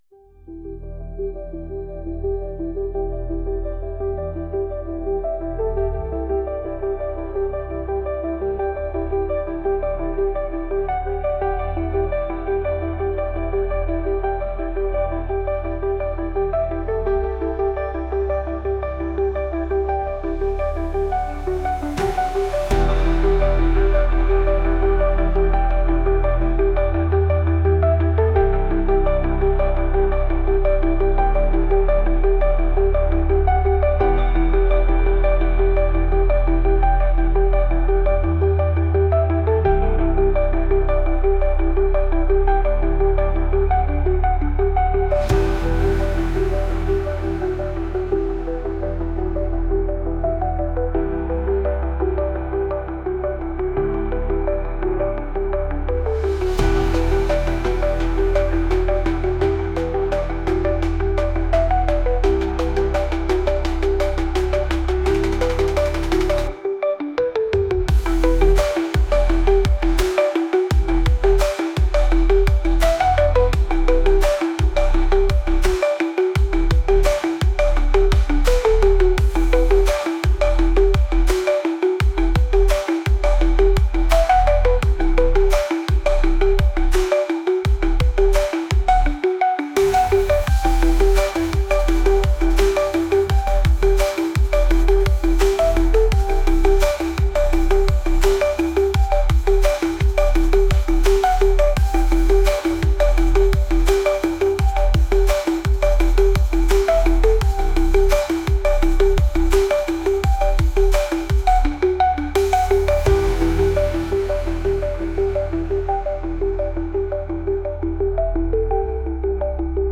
ETC-ELECTRONIC-1.mp3